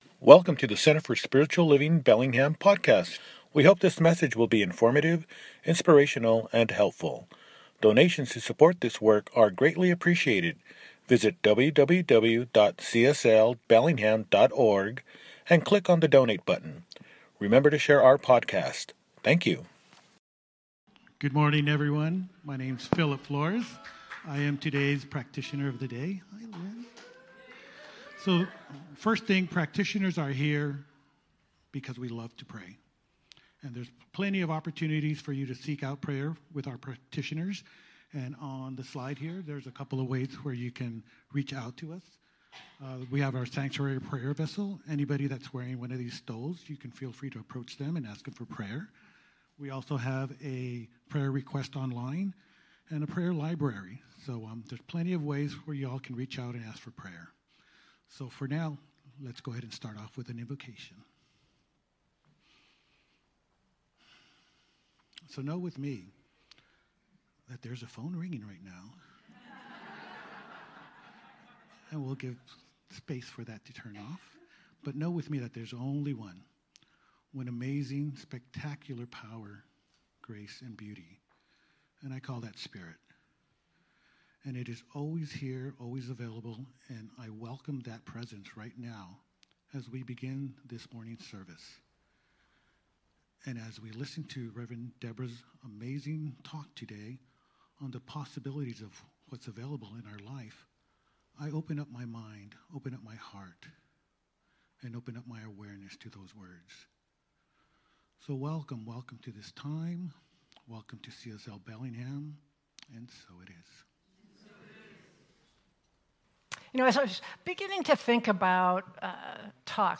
Apr 6, 2025 | Podcasts, Services